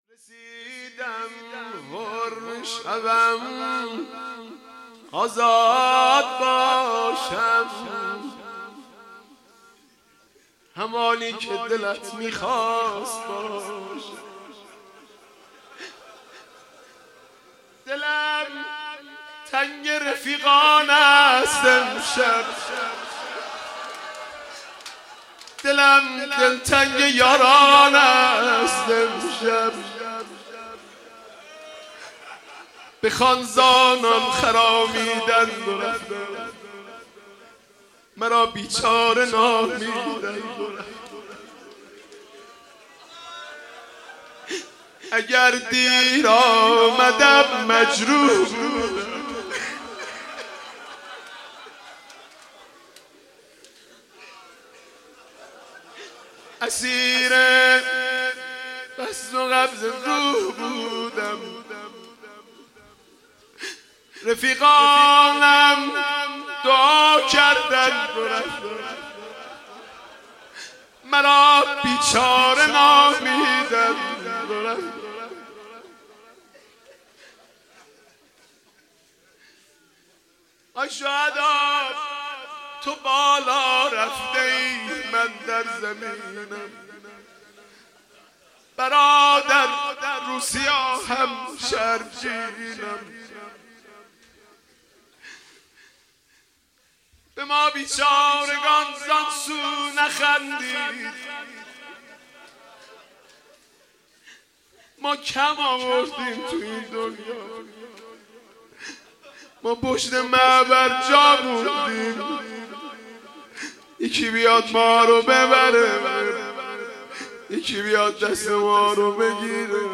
خیمه گاه - حاج مهدی رسولی - بخش اول - نجوا (رسیدم حر شوم آزاد باشم)
شب هفتم محرم 1397